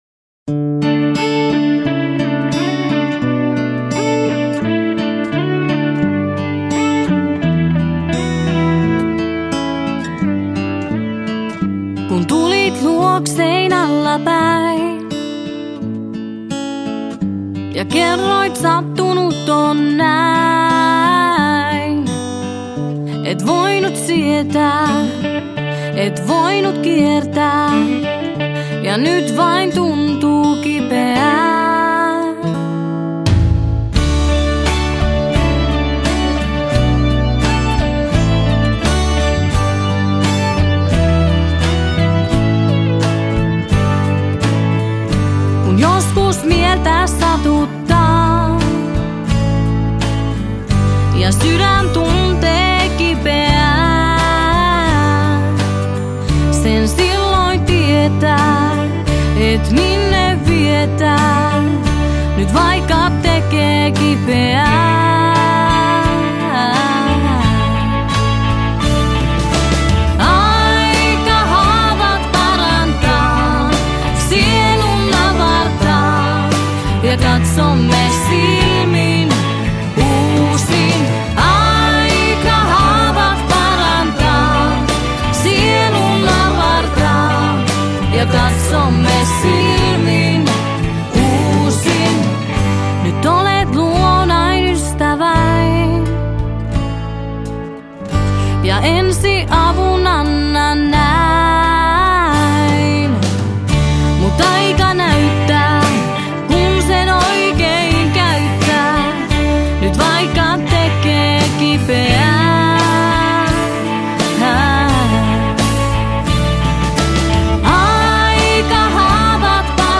laulut
kaikki soittimet, rumpujen ohjelmointi, taustalaulut